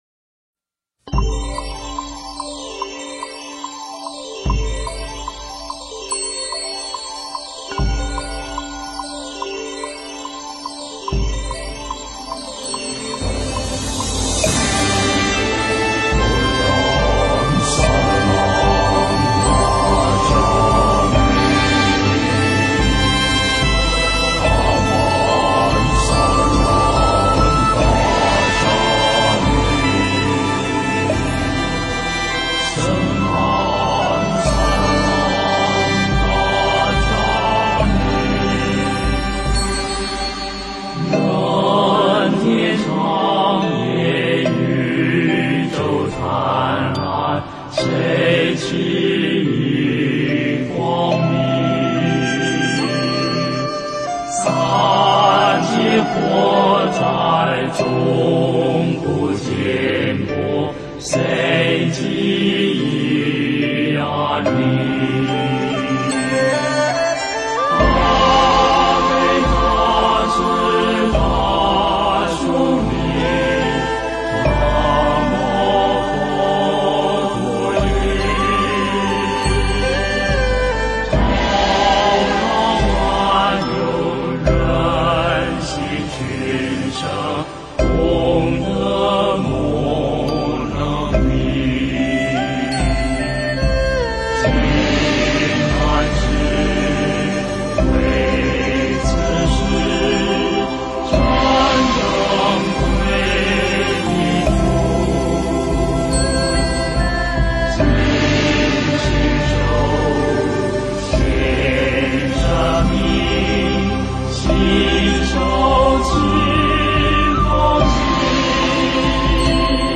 三宝歌 诵经 三宝歌--佛教音乐 点我： 标签: 佛音 诵经 佛教音乐 返回列表 上一篇： 菩提本无树 下一篇： 时轮金刚咒 相关文章 印光法师文钞11 印光法师文钞11--净界法师...